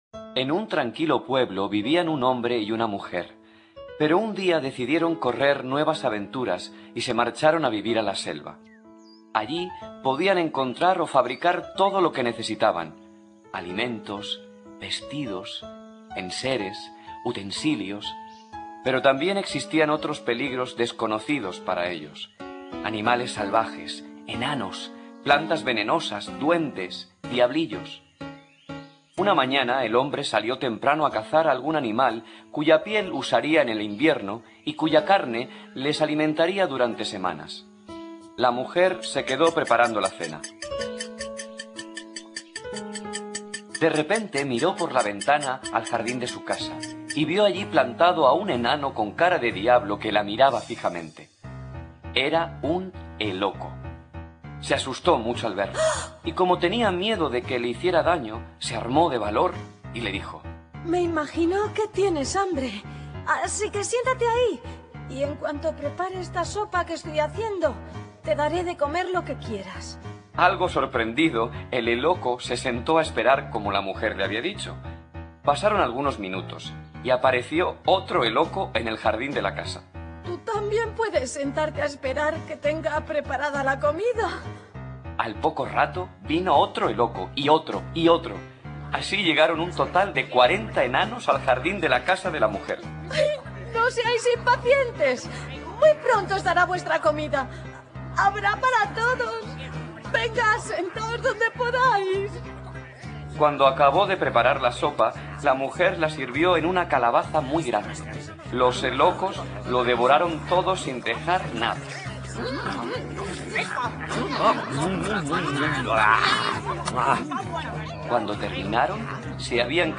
zumbido
narrador
Cuentos infantiles